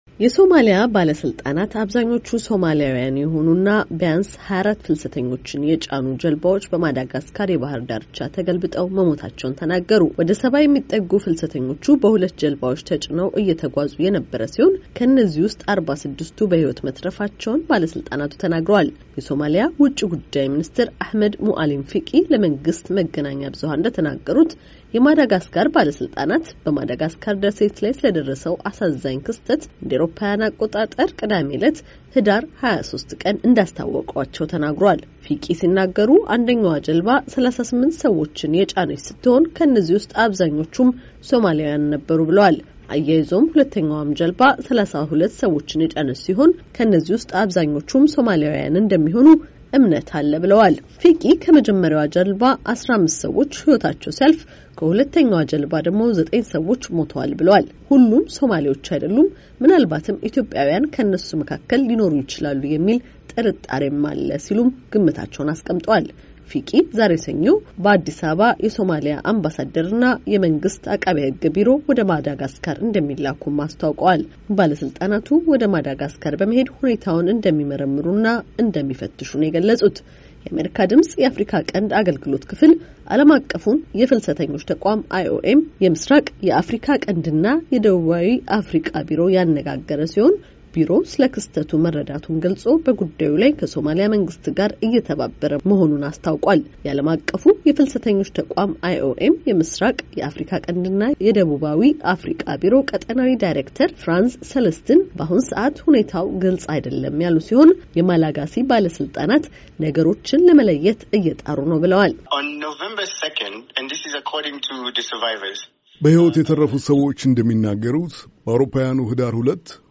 ዘገባ ከተያያዘው ፋይል ይከታተሉ።